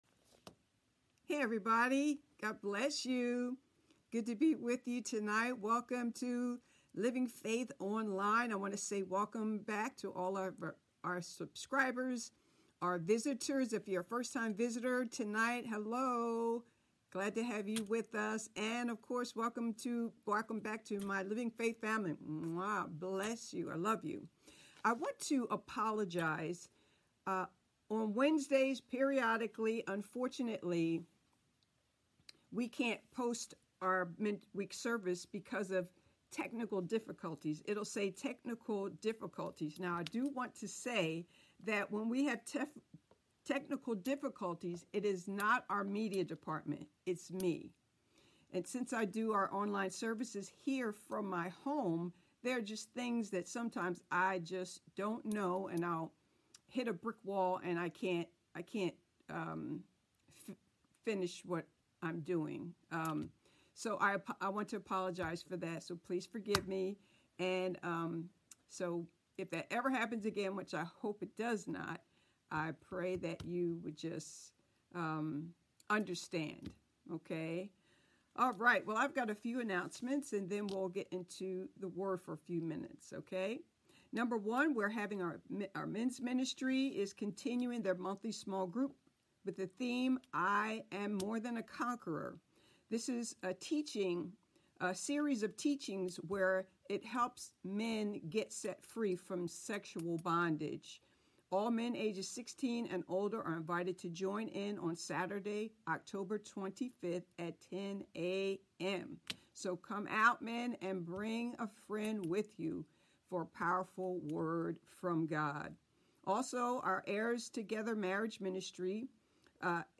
Sermons | Living Faith Christian Center